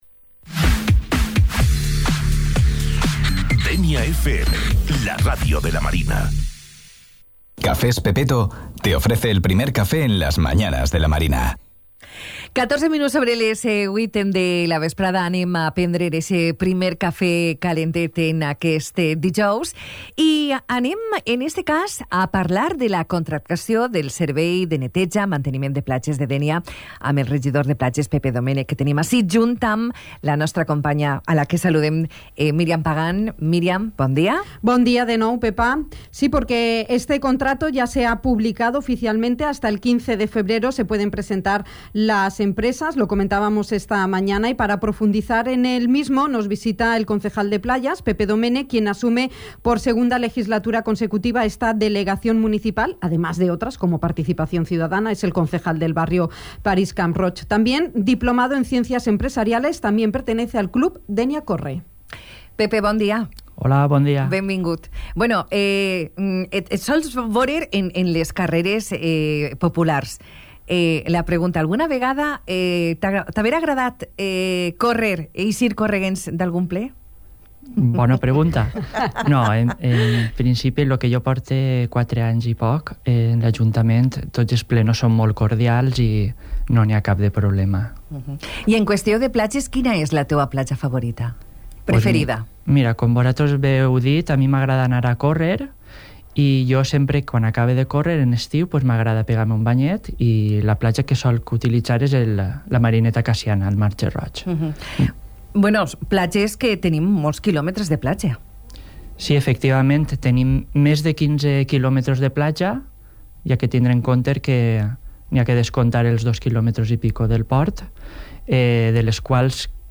En la mañana del 18 de enero, ha visitado el ‘primer café’ de Dénia FM, el concejal de Playas del Ayuntamiento dianense, Pepe Doménech para profundizar en este nuevo contrato, con un precio de licitación de 11,5 millones de euros, IVA incluido, y una vigencia de 10 años.
Entrevista-Pepe-Domenech.mp3